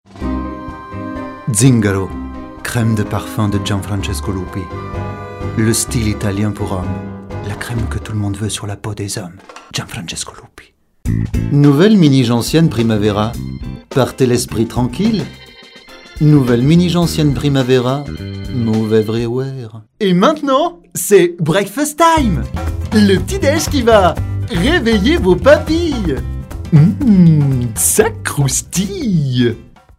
VOICE OVER FRENCH ITALIAN ACTOR DUBBING VERSATILE VOICE YOUNG VOICE MIDDLE VOICE OLDER VOICE
Sprechprobe: Werbung (Muttersprache):